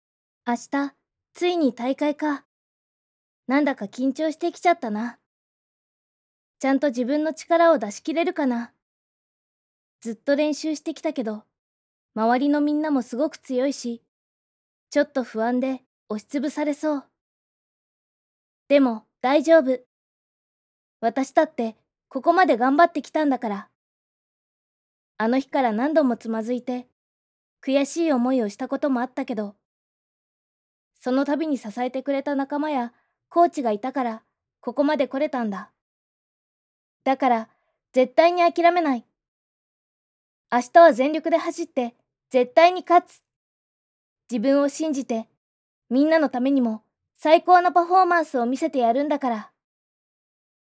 感情を込めずに音読してみましょう。
【素読み】